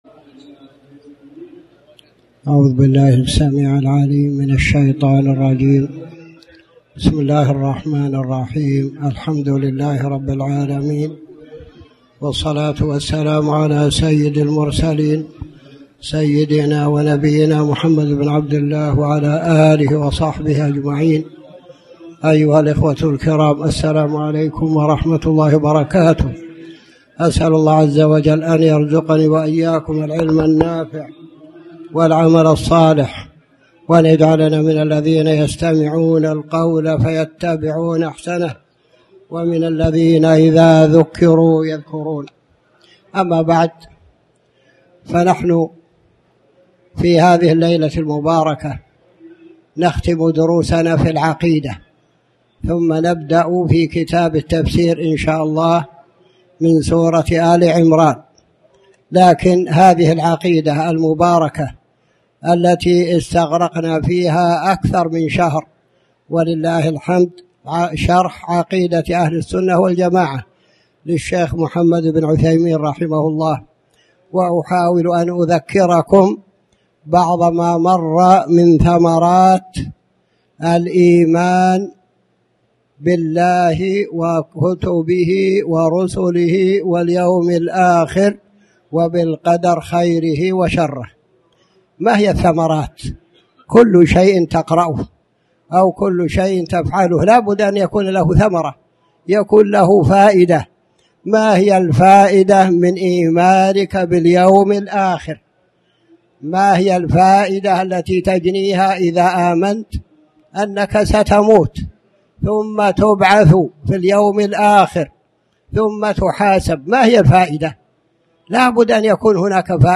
تاريخ النشر ١٠ ربيع الأول ١٤٣٩ هـ المكان: المسجد الحرام الشيخ